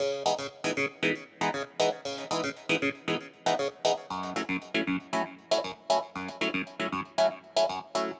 04 Clavinet PT1.wav